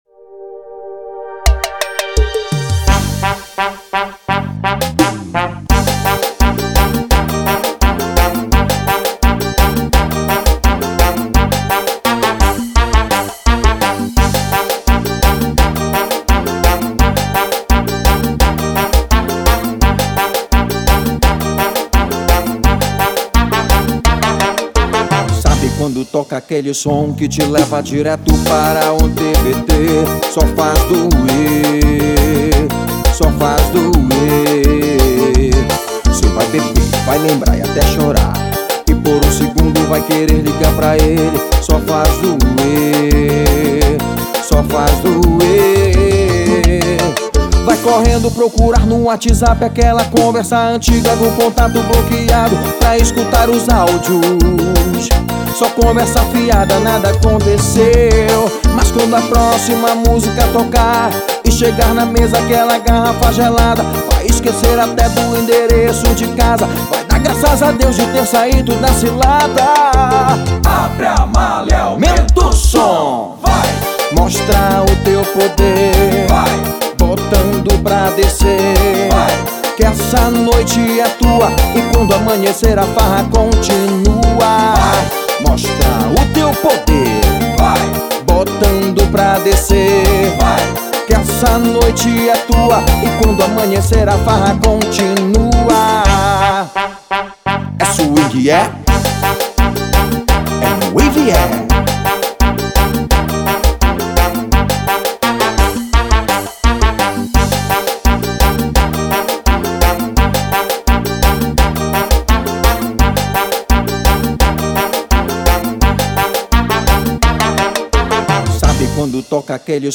EstiloSwingueira